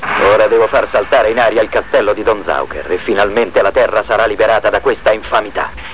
Type: Sound Effect